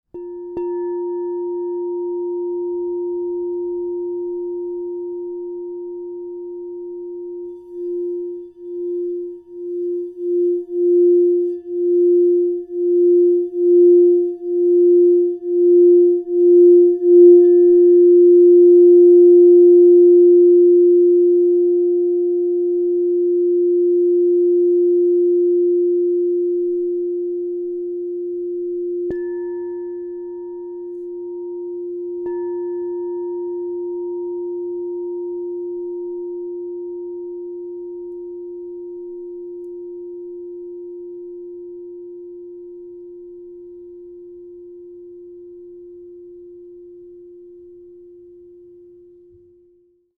Platinum 7″ F +10 Crystal Tones singing bowl
Immerse yourself in the harmonizing resonance of the Crystal Tones® Platinum 7 inch F True Tone Singing Bowl, resonating at F +10 to inspire clarity, renewal, and emotional balance.
The 7-inch size delivers focused and resonant tones, making it ideal for personal meditation, sound therapy, and sacred rituals.
Enhance your journey with this 7″ Crystal Tones® True Tone alchemy singing bowl made with Platinum in the key of F +10.